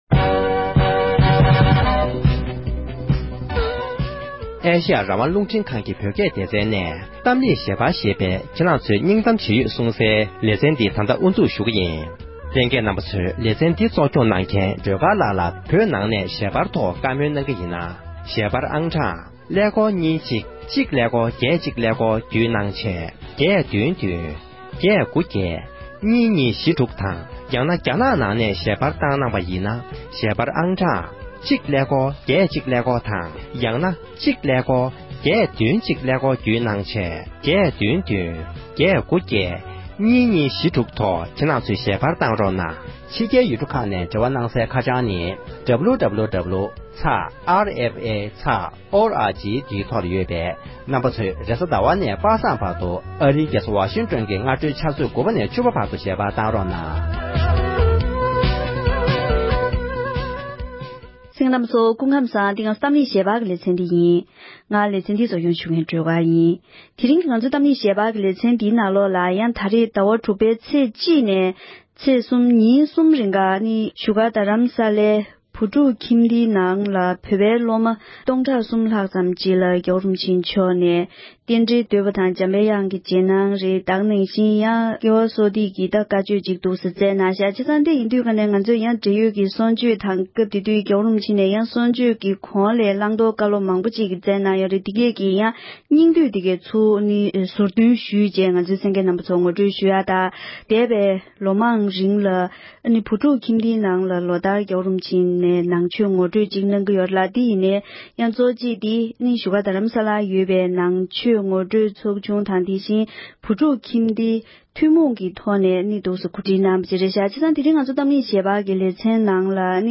འབྲེལ་ཡོད་མི་སྣར་བཀའ་འདྲི་ཞུས་པའི་དུམ་བུ་གཉིས་པ་གསན་རོགས་ཞུ༎